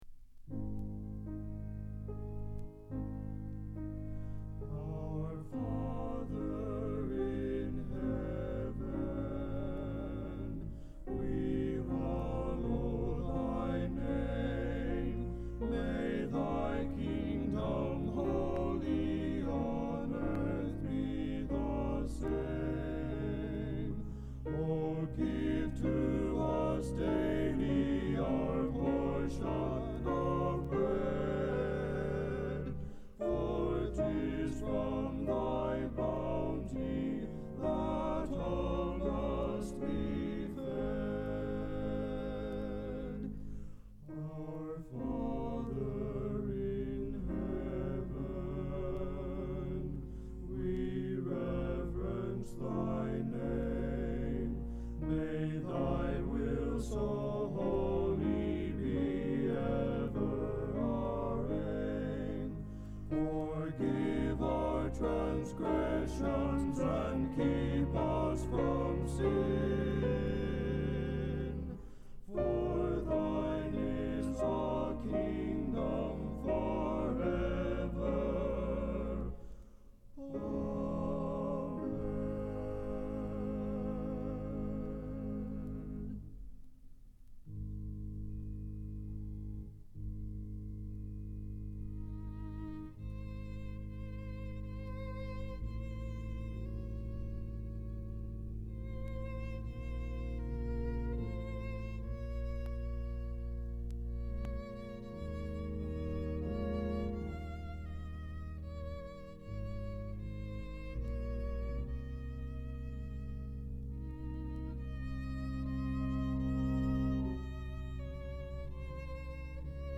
THE COLLEGE MEN FOUR is the official travelling represent,ative of Bethany Nazarene College located in Bethany, Oklahoma.